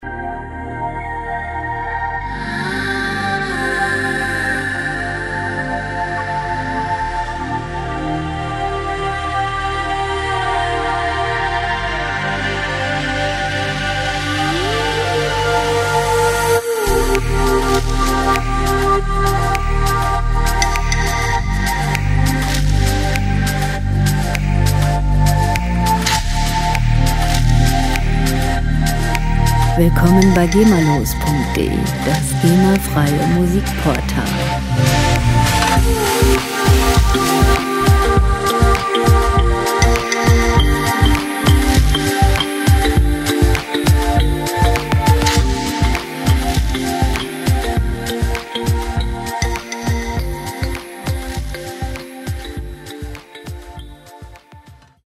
• Ambient Pop